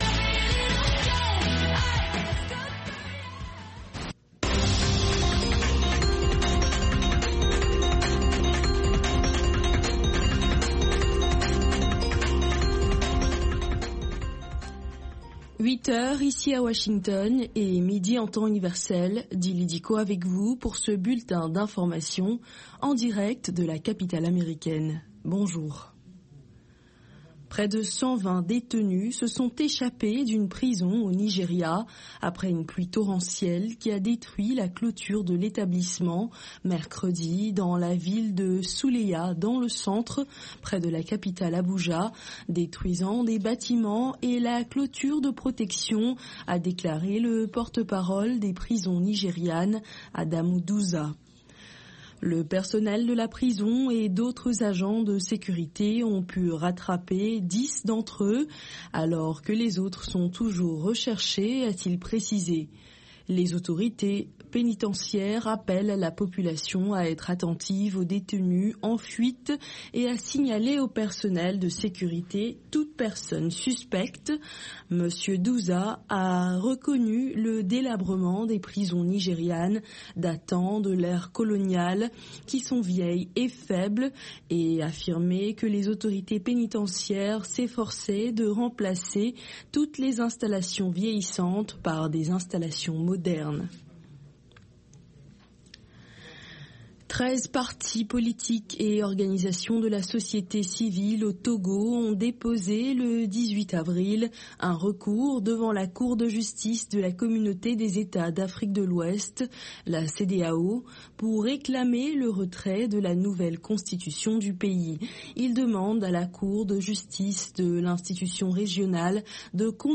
10 Minute Newscast